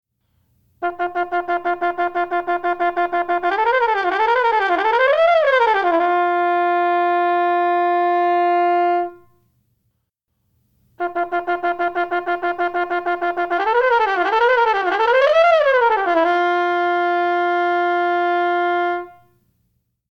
The result is a throaty timbre that, depending on the mouthpiece, can be somewhat nasally, but is generally appealing.
Playing Samples -- Yamaha 204 vs. Quantum 5050
In each of these mp3 samples, the Yamaha 204 is heard first, followed by the Quantum 5050 (both used with a Monette B2 Flumpet mouthpiece).
Runs